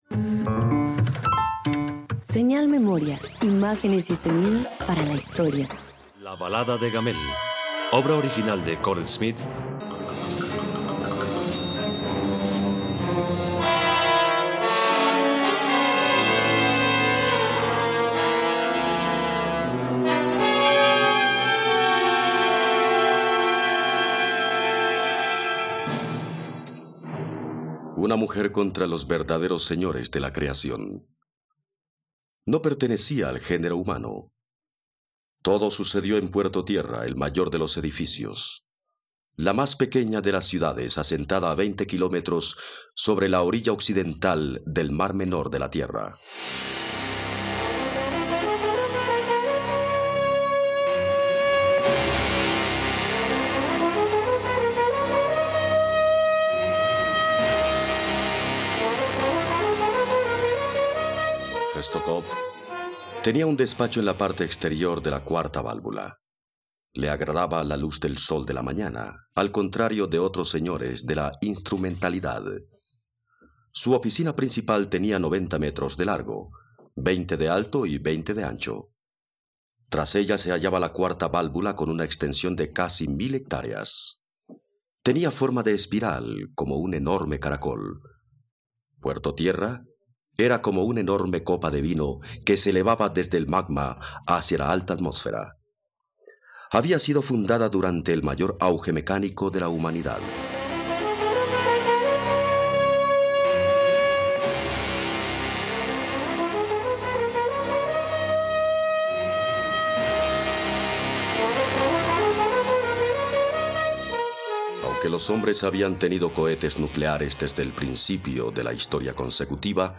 ..Radioteatro. Escucha 'La Balada de Gamel'